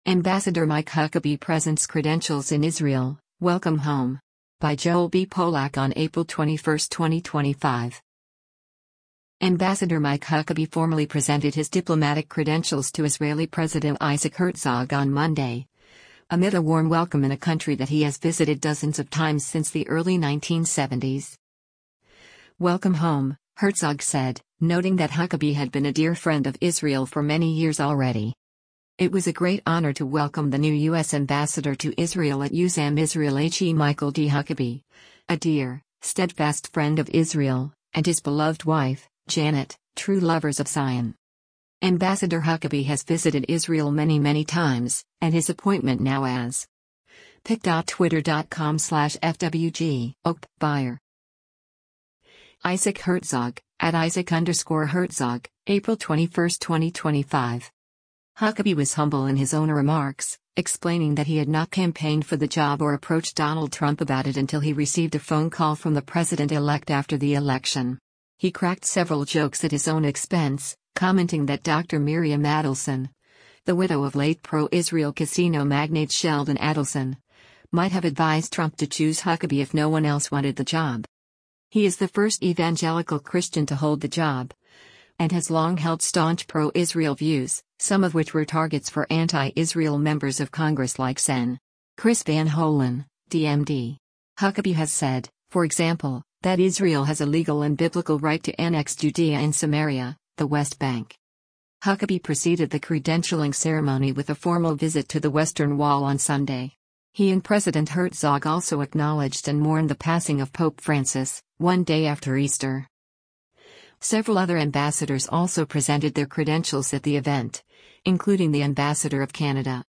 Ambassador Mike Huckabee formally presented his diplomatic credentials to Israeli President Isaac Herzog on Monday, amid a warm welcome in a country that he has visited dozens of times since the early 1970s.
He cracked several jokes at his own expense, commenting that Dr. Miriam Adelson, the widow of late pro-Israel casino magnate Sheldon Adelson, might have advised Trump to choose Huckabee if no one else wanted the job.